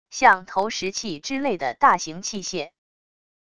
像投石器之类的大型器械wav音频